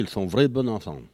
Catégorie Locution